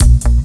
DUBLOOP 04-L.wav